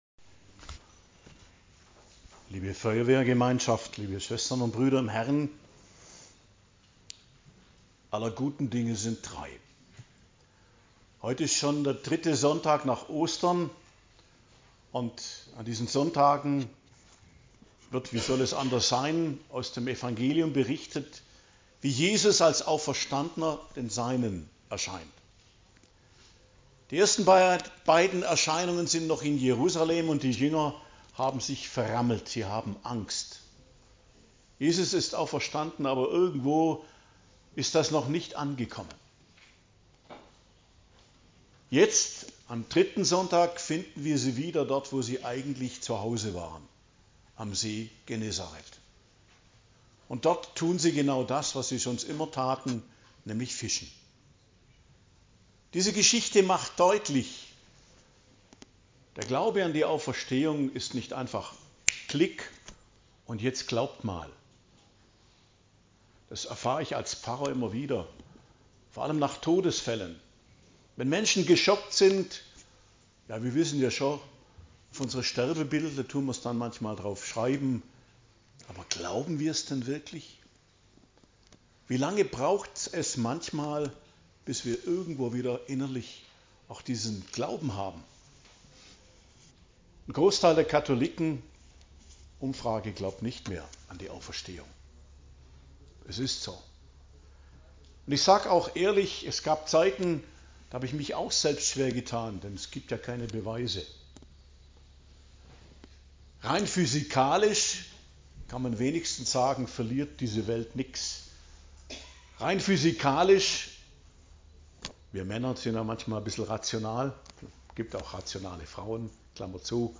Predigt zum 3. Sonntag der Osterzeit, 4.05.2025